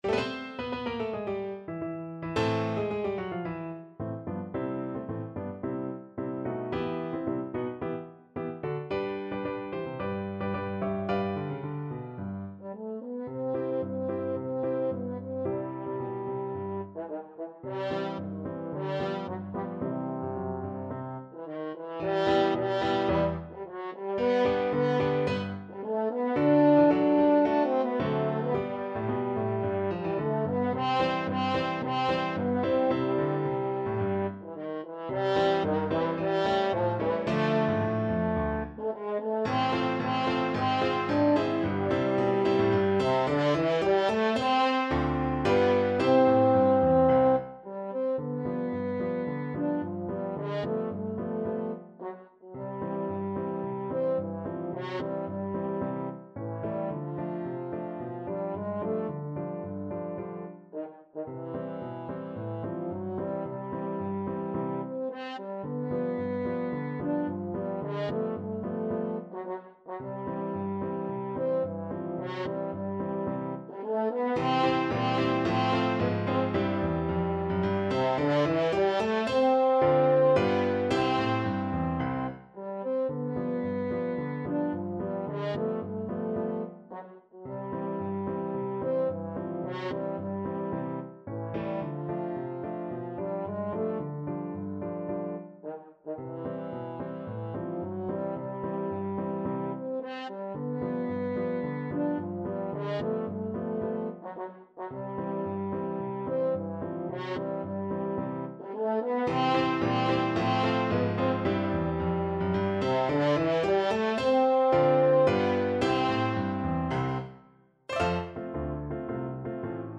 2/2 (View more 2/2 Music)
Classical (View more Classical French Horn Music)